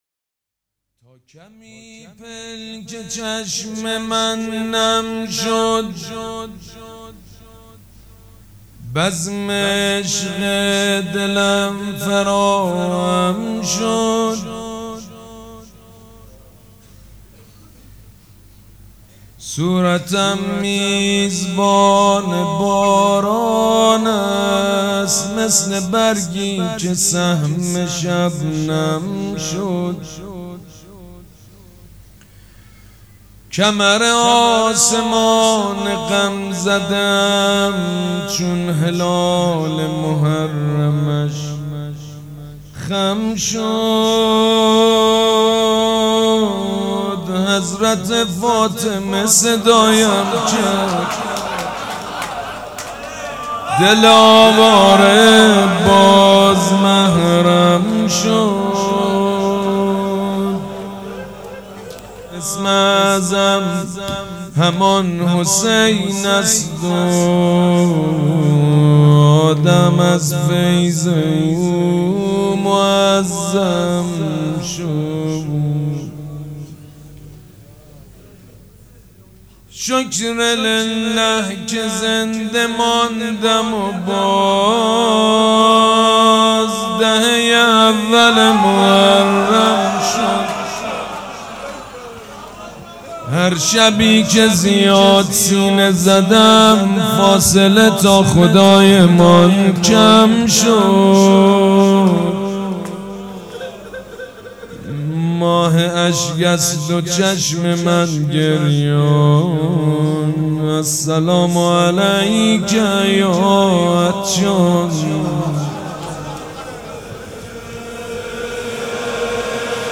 روضه بخش دوم
حاج سید مجید بنی فاطمه جمعه 16 شهریور 1397 هیئت ریحانه الحسین سلام الله علیها
سبک اثــر روضه مداح حاج سید مجید بنی فاطمه